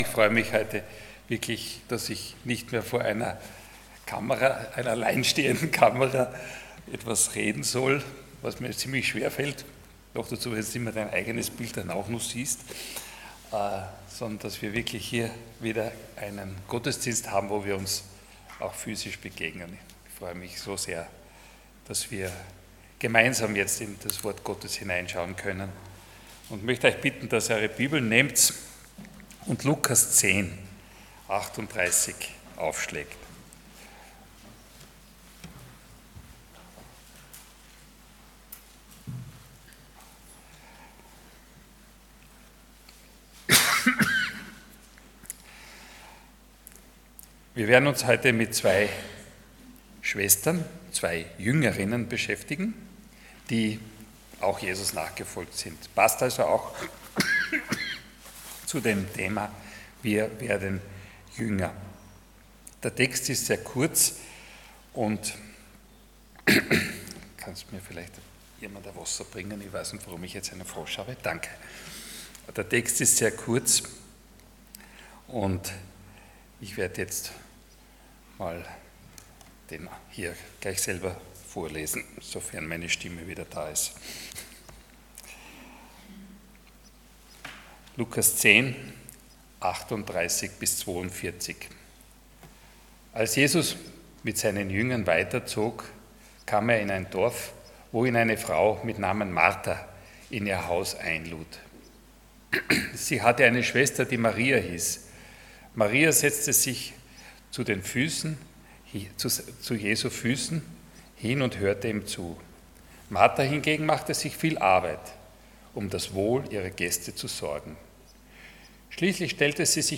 Luke 10:38-42 Dienstart: Sonntag Morgen %todo_render% Martha und Maria « Schon wieder ein Geschenk